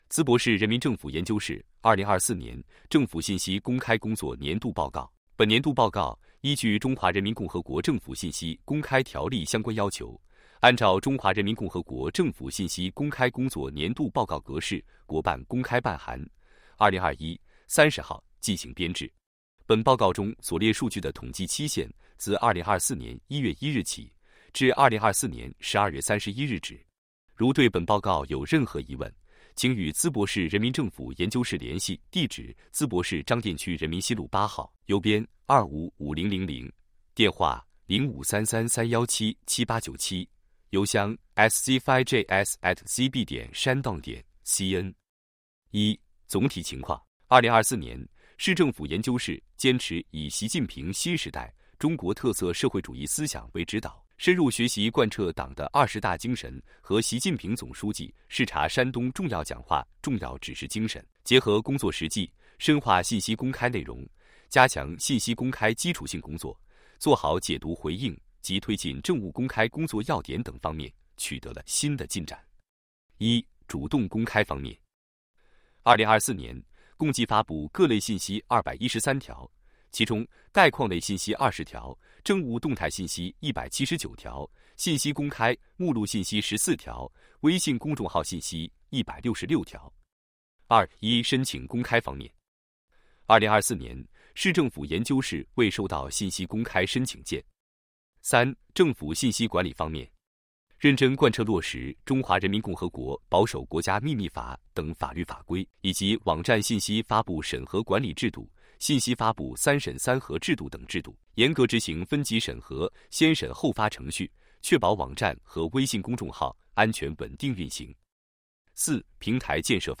语音播报   |   图片解读